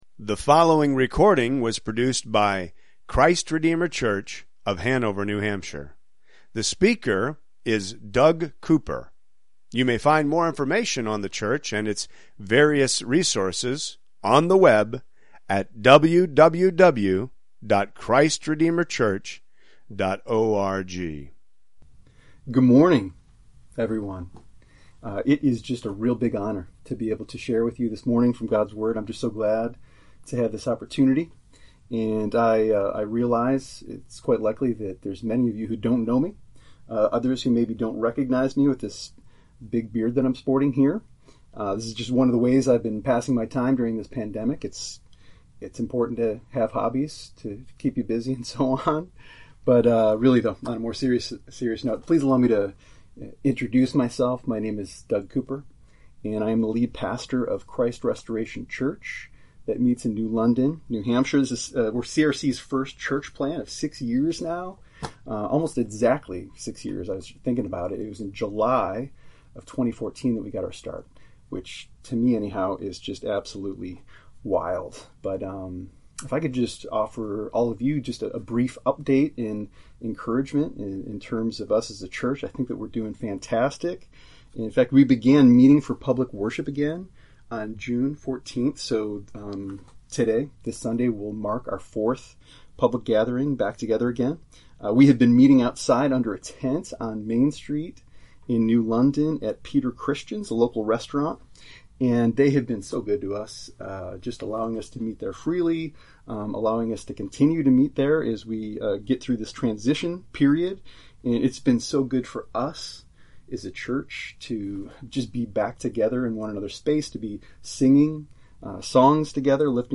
Guide for Home Worship July 5, 2020
Please note: the song and sermon audio may not be available until Saturday evening.